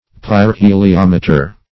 Search Result for " pyrheliometer" : The Collaborative International Dictionary of English v.0.48: Pyrheliometer \Pyr*he`li*om"e*ter\, n. [Gr.